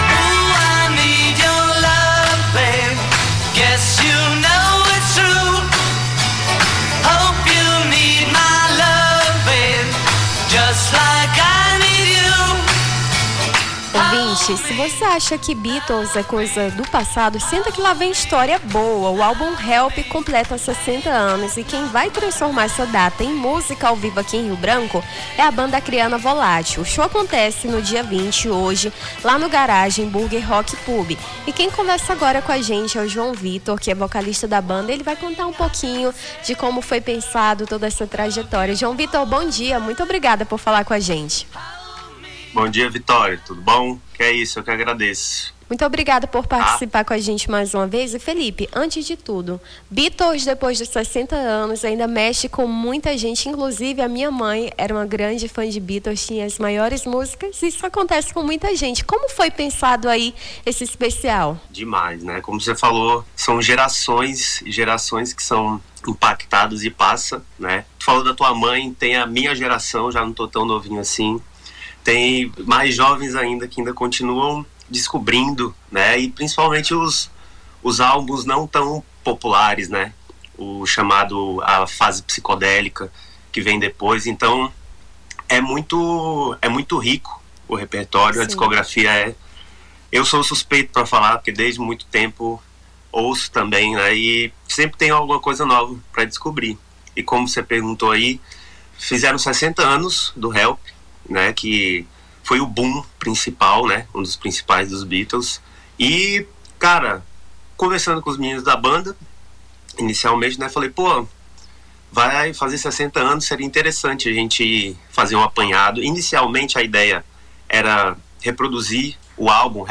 Nome do Artista - CENSURA - ENTREVISTA SHOW ESPECIAL BEATLES (20-12-25).mp3